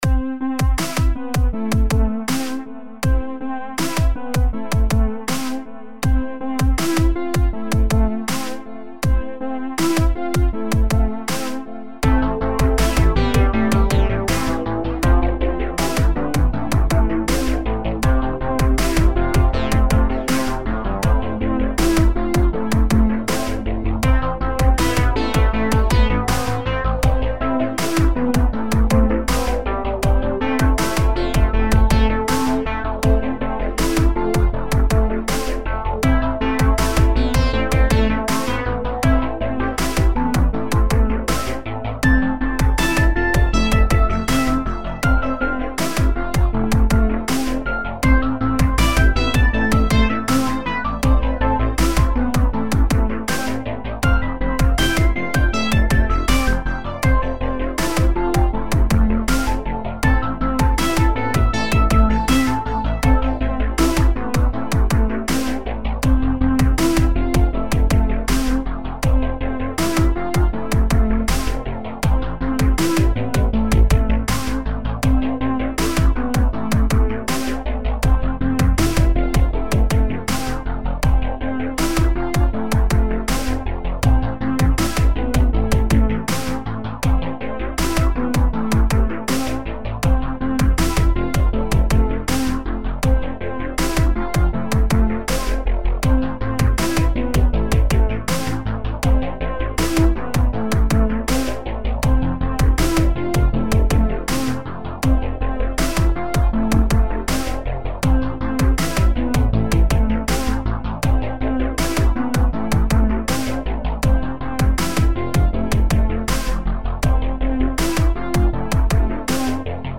Vocals and guitar on its wayyyyyyyy.